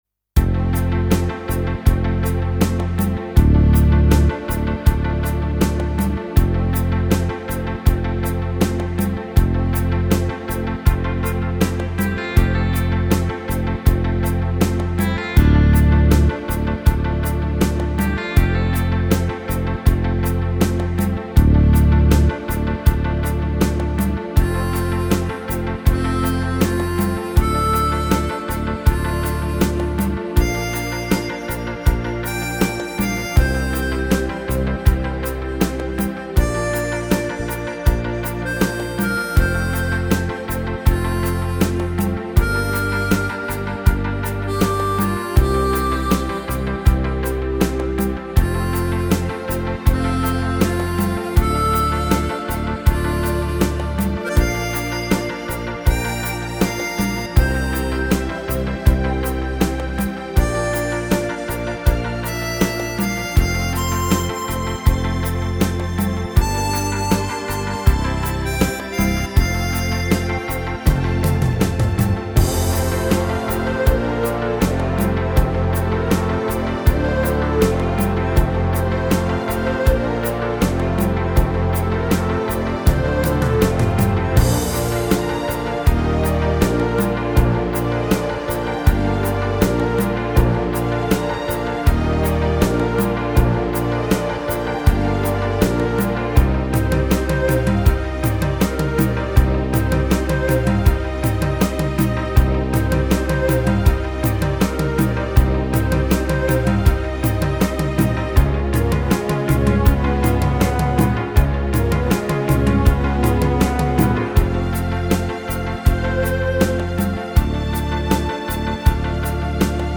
Musique de film